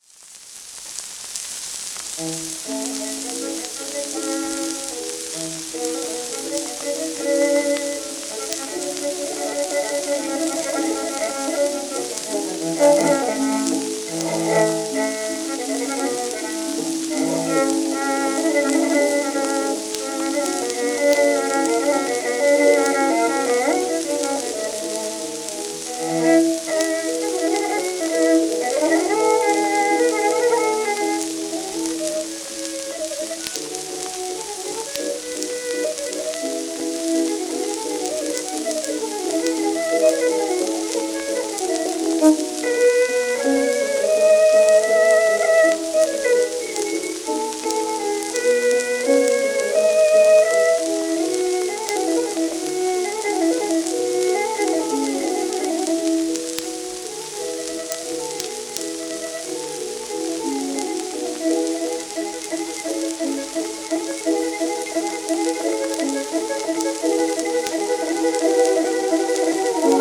w/ピアノ
1916年録音 80rpm